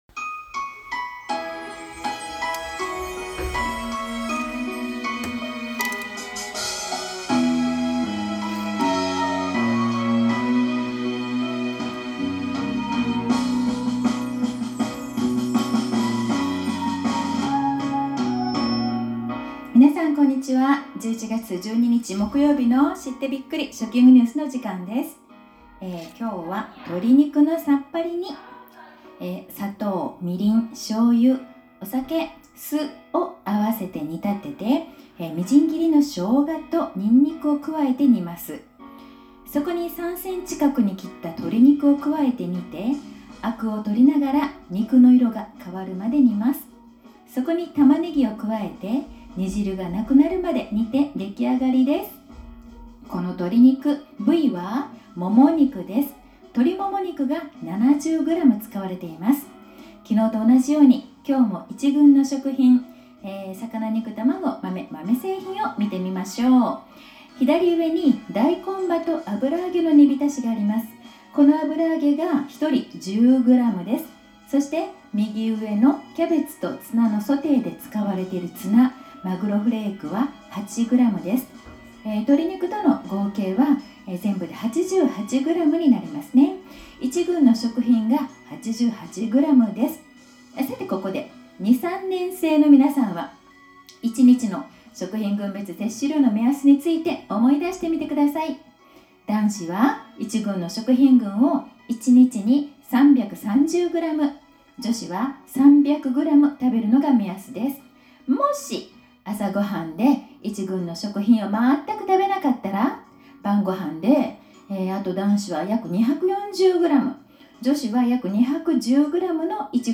放送では，給食の１群（※）の食品の量や，朝ご飯から「蛋白質を摂れる食品」を食べることの大切さについてお話しています。